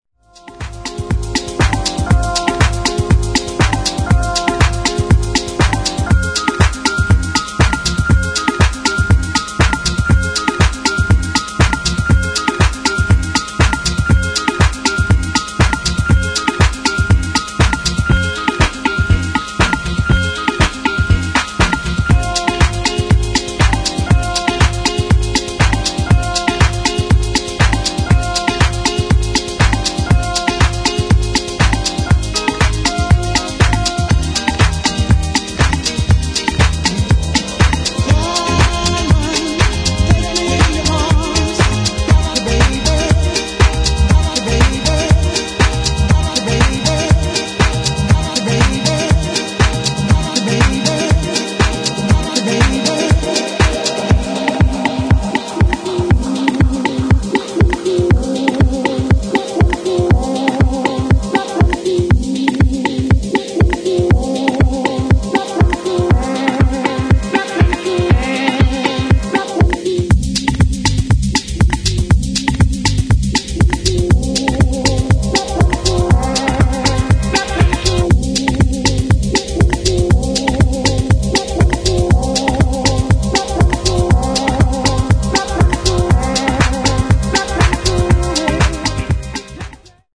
[ DISCO / FUNK ]